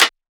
toomp clap.wav